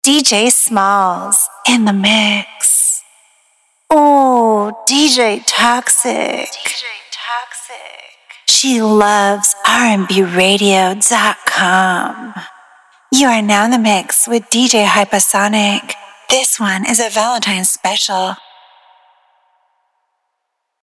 Seductive Female DJ Drops - Sexy American Voice
Stand out with our personalized female DJ drops, professionally recorded by an American voice artist. Authentic accent.
2026-Seductive-female-drops.mp3